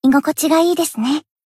贡献 ） 分类:蔚蓝档案 分类:蔚蓝档案语音 协议:Copyright 您不可以覆盖此文件。
BA_V_Chinatsu_Cafe_Monolog_3.ogg